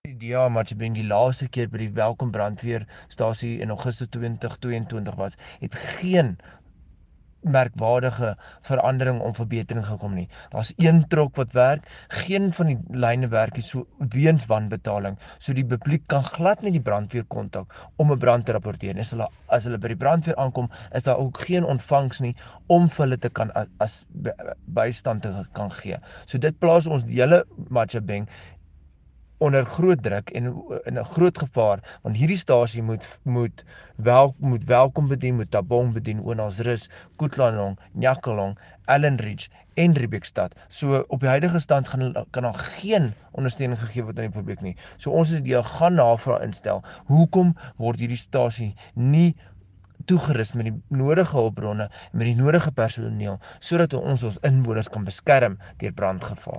Afrikaans soundbites by Cllr Igor Scheurkogel and